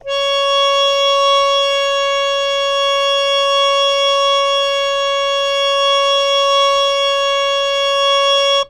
interactive-fretboard / samples / harmonium / Cs5.wav
Cs5.wav